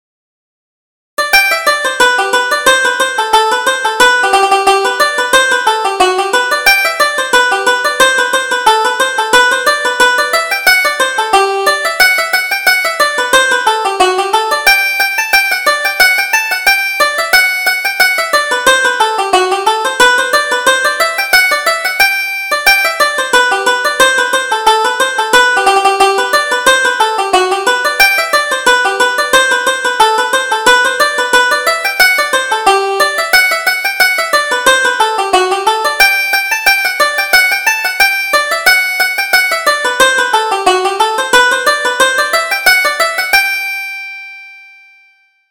Reel: Bridget McBride